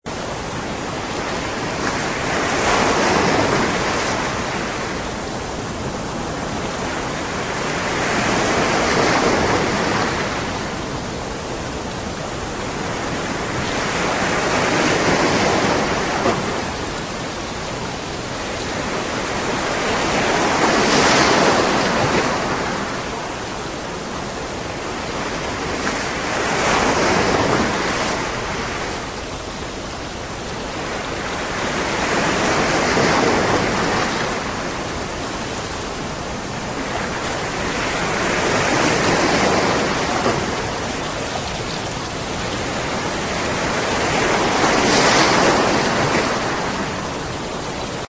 ambient_jungle.ogg